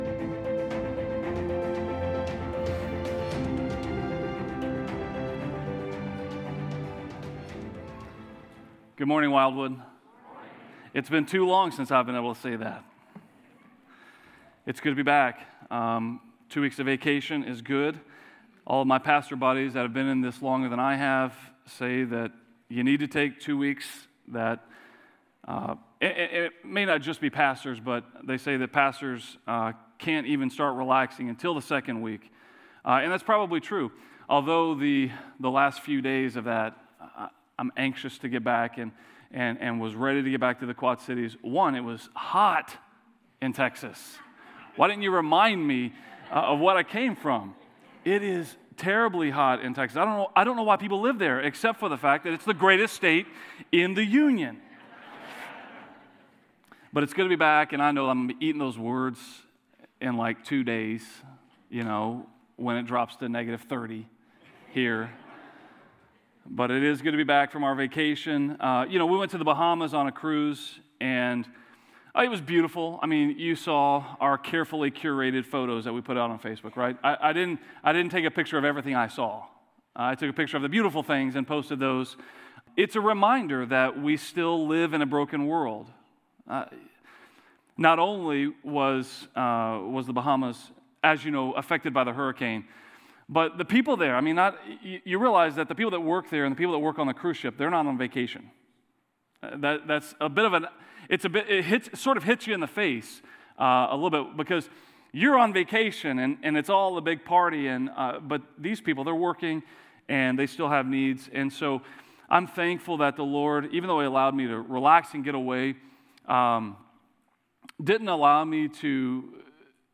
A message from the series "To Seek and To Save."